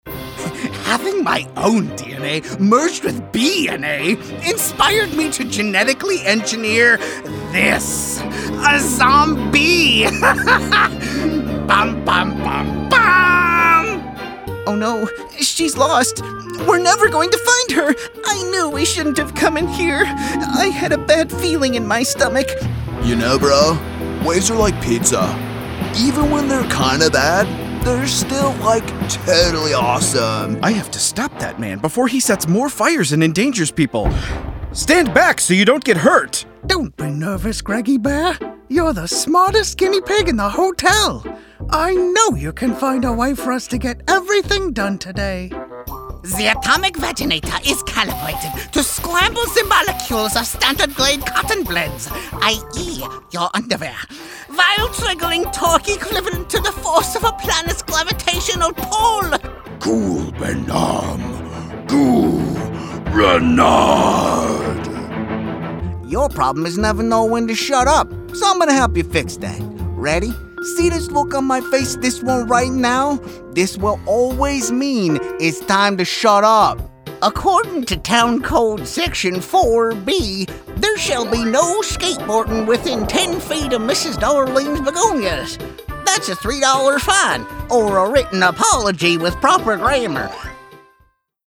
VOICE ACTOR
Animation Demo
Microphones: RODE NT1, Warm Audio WA-47jr